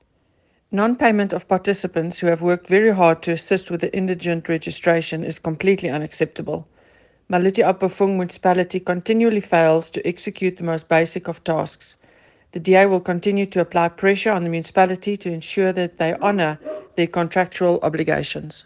Issued by Eleanor Quinta – DA Councillor Maluti-a-Phofung Local Municipality
Afrikaans soundbites by Cllr Eleanor Quinta and